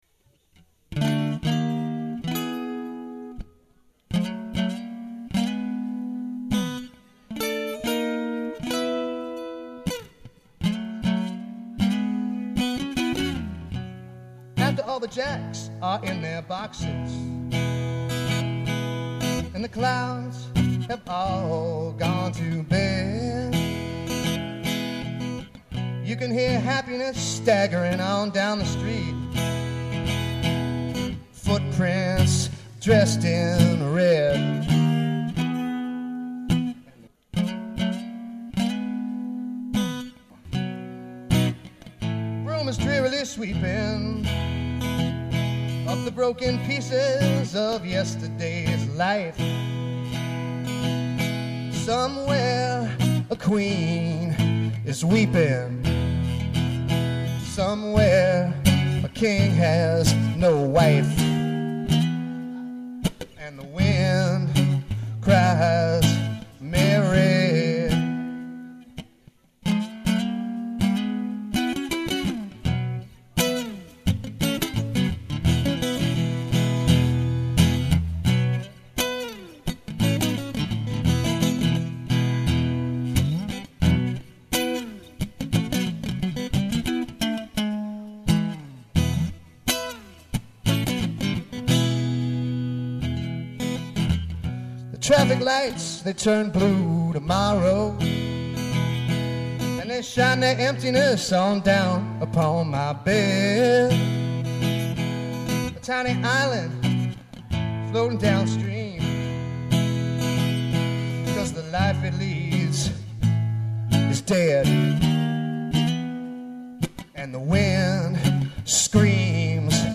Per Your Request some highlights from Friday's show.